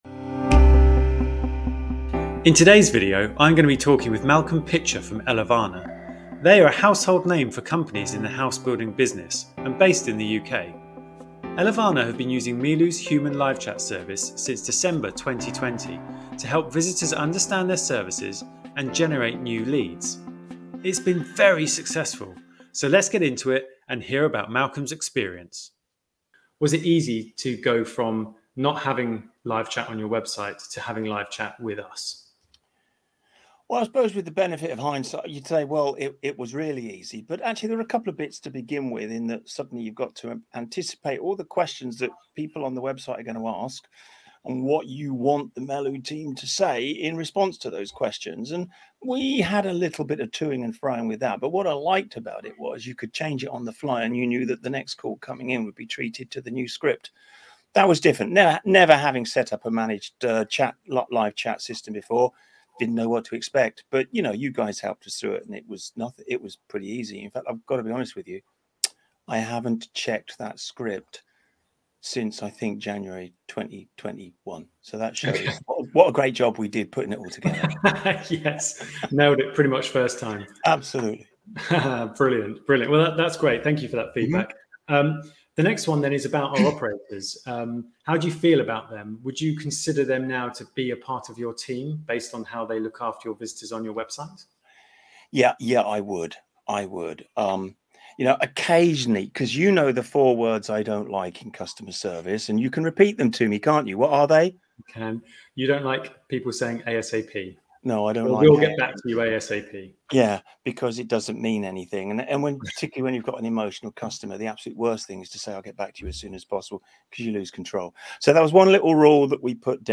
Client Case Study with Elevana